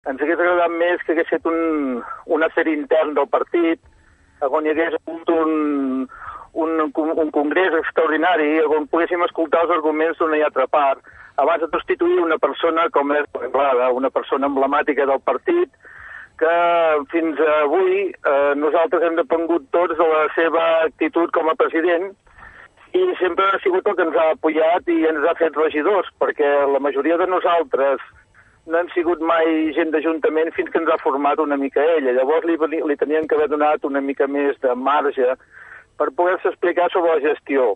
Josep Ricart, ha confirmat en una entrevista al programa L'andana que seguirà al grup municipal de PxC i no es planteja desvincular-se'n. El portaveu del partit xenòfob a l'Ajuntament de Taradell, però, reconeix que l'expulsió d'Anglada no s'ha fet bé.